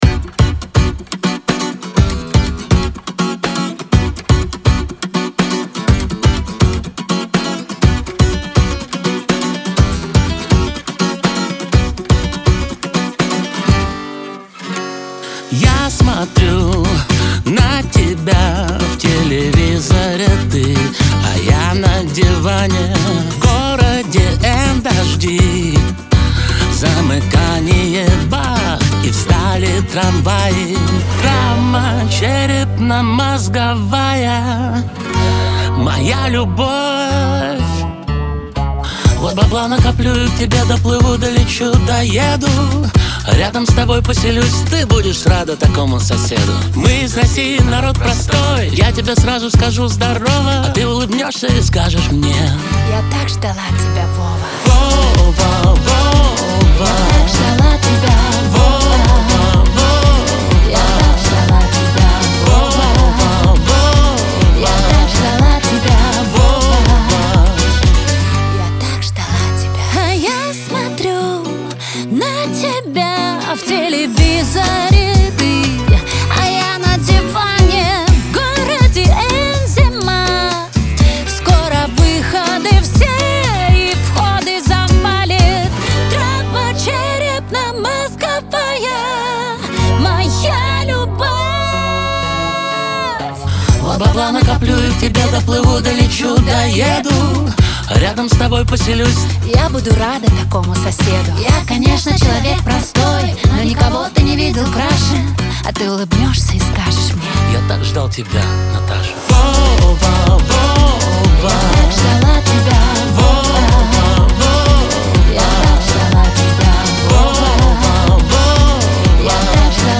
дуэт
pop , Кавер-версия , эстрада